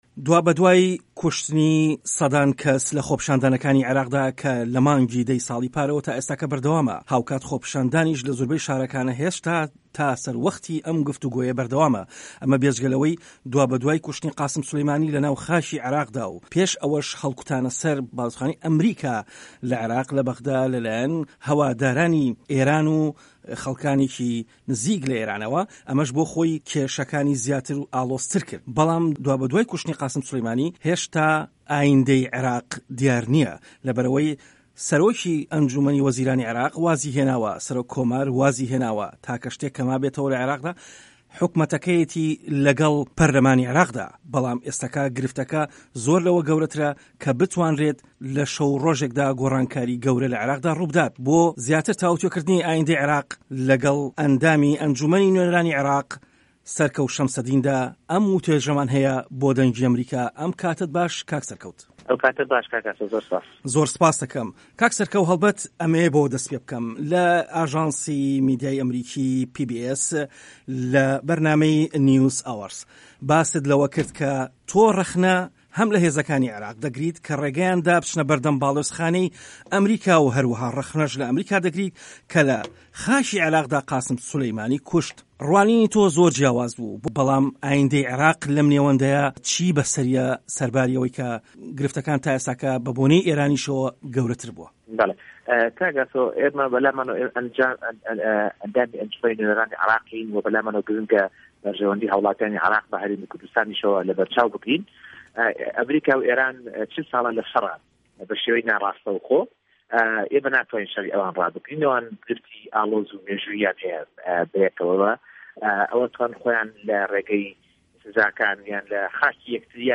وتووێژی سه‌رکه‌وت شه‌مسه‌دین